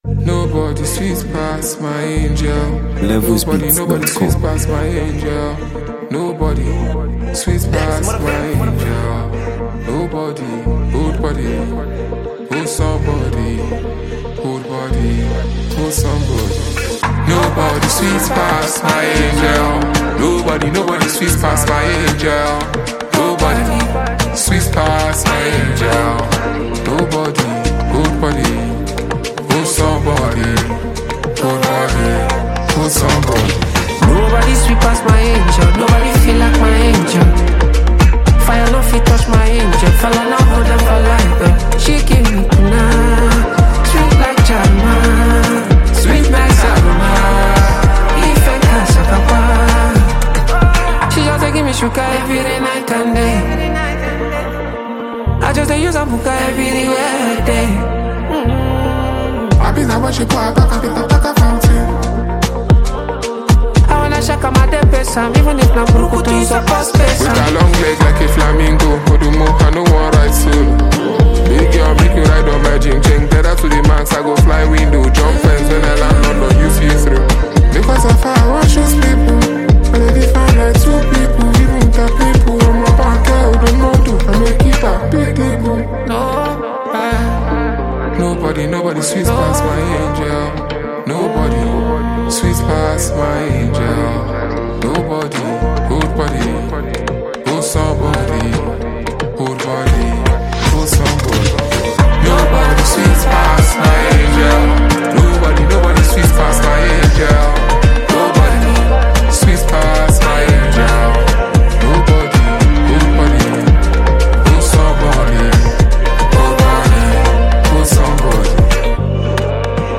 On this soulful record